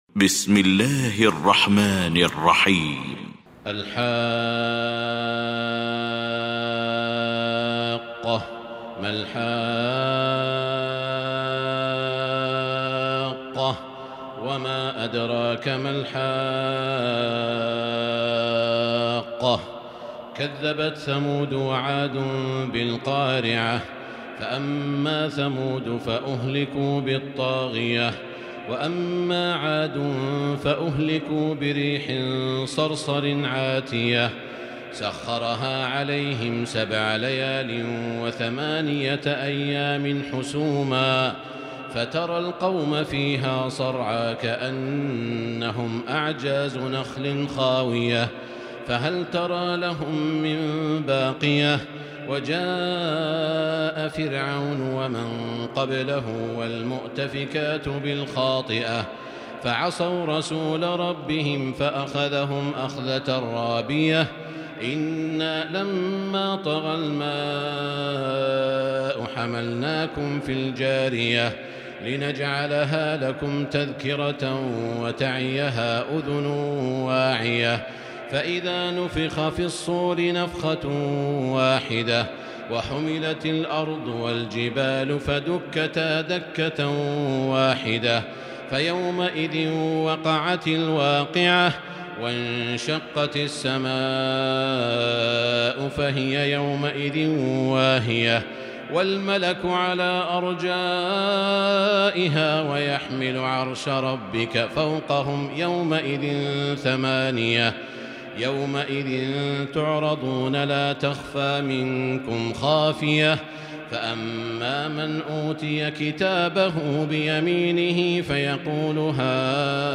المكان: المسجد الحرام الشيخ: سعود الشريم سعود الشريم الحاقة